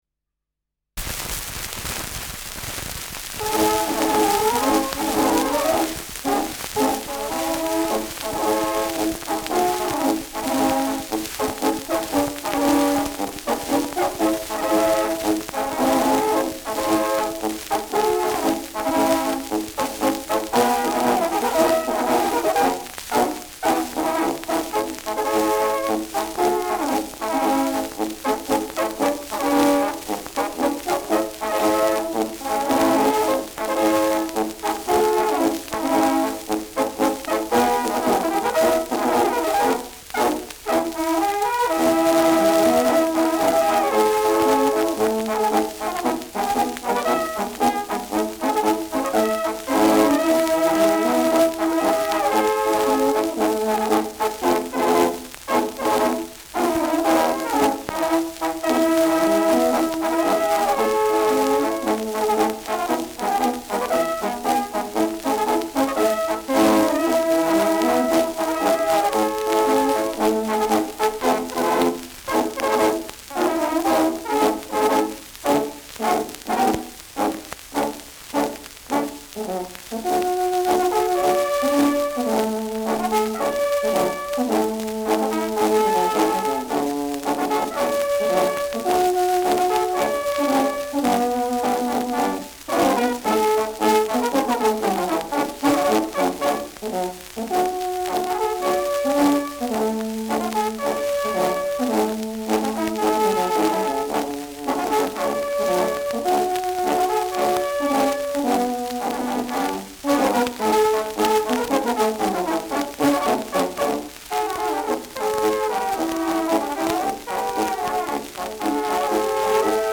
Schellackplatte
präsentes Rauschen
Andorfer Bauernkapelle (Interpretation)